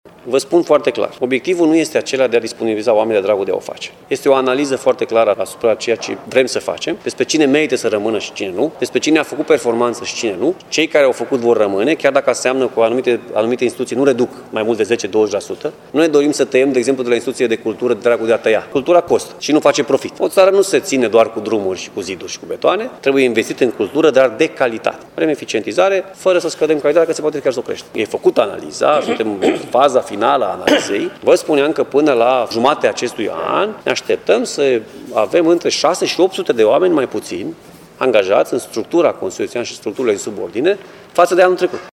Președintele Consiliului Județean Timiș spune că analiza se află deja în faza finală.